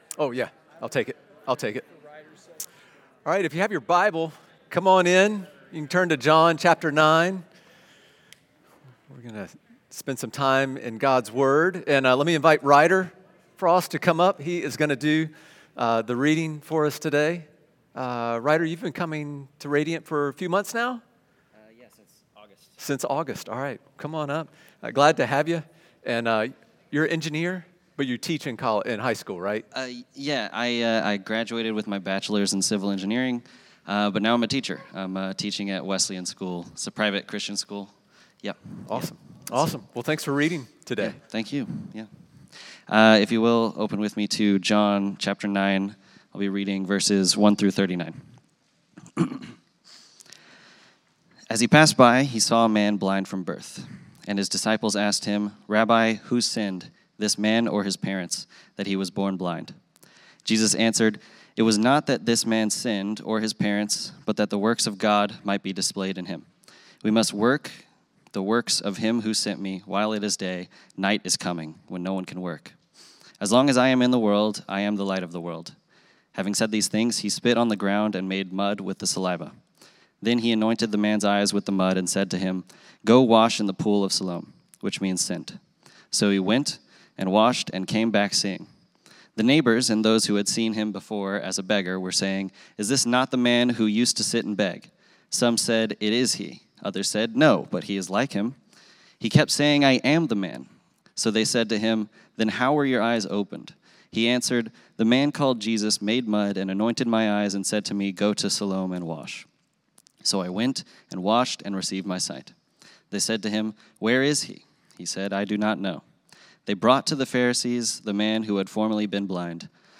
Sermons | Radiant Church